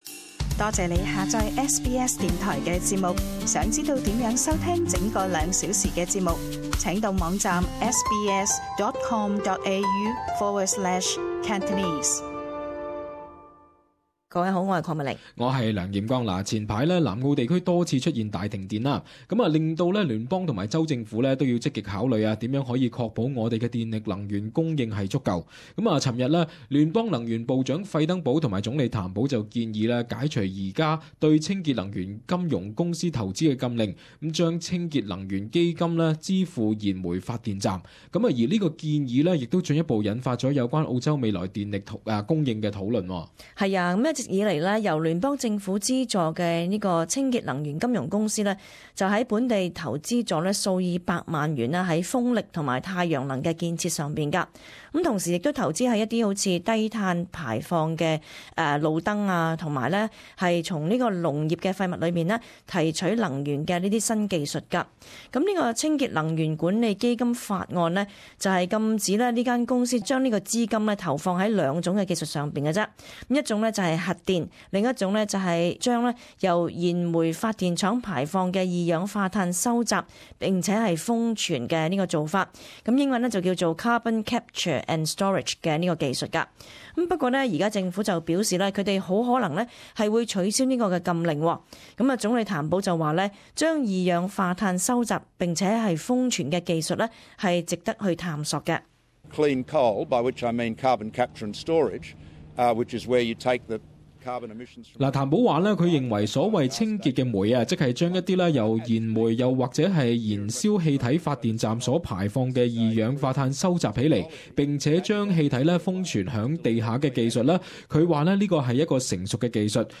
【時事報導】 聯邦政府能源政策走回頭路？